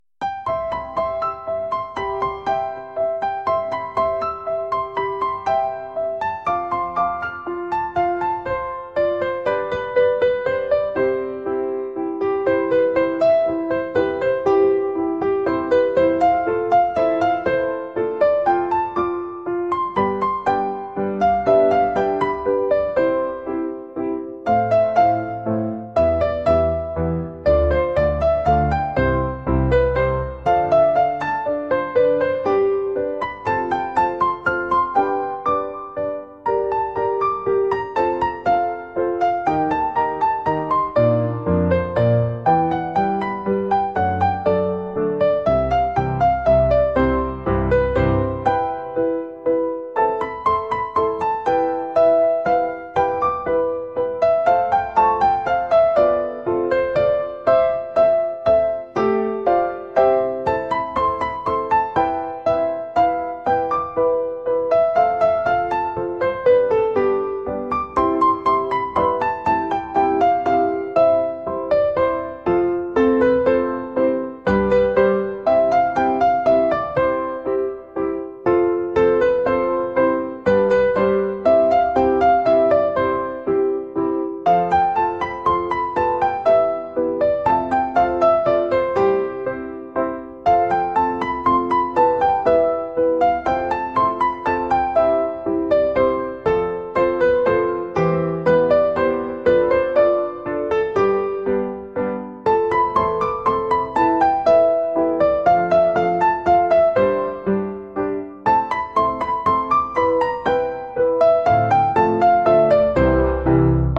「ほのぼの」